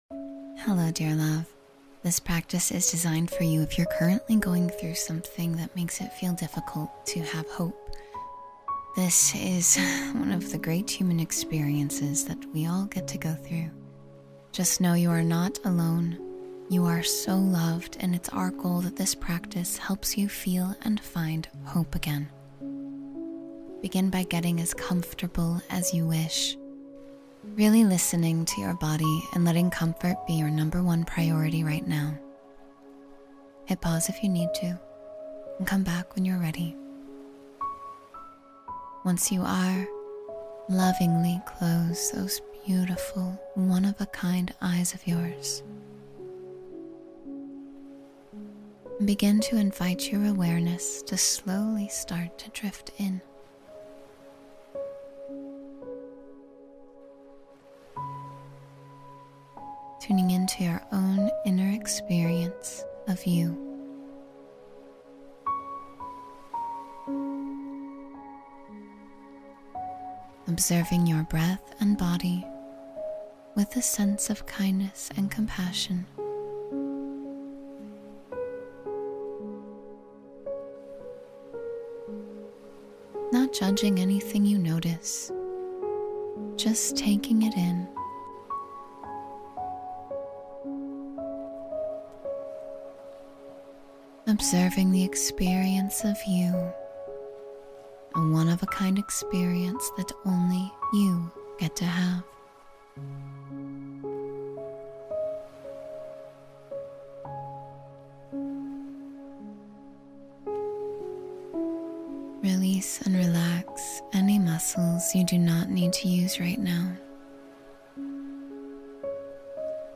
When Hope Fades, Turn Inward and Heal — Meditation for Inner Strength